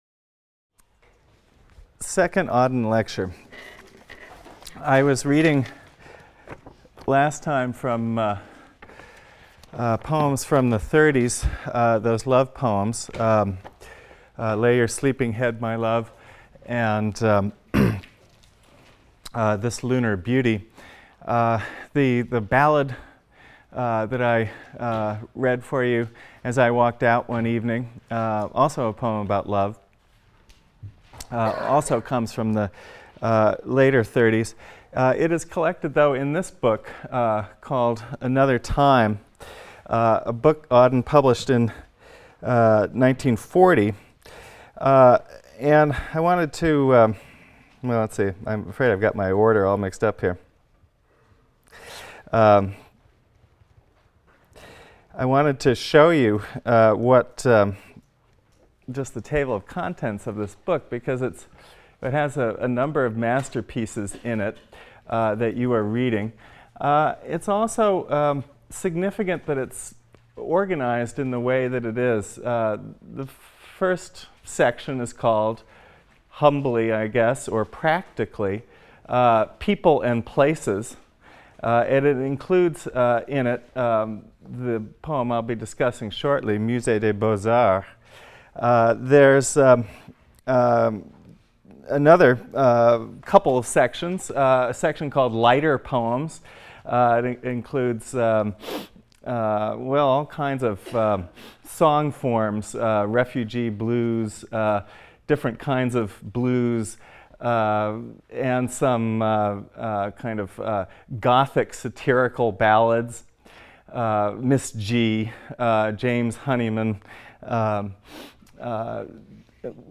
ENGL 310 - Lecture 23 - W. H. Auden (cont.) | Open Yale Courses